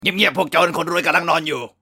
เสียงแจ้งเตือนเงียบๆพวกจน
หมวดหมู่: เสียงเรียกเข้า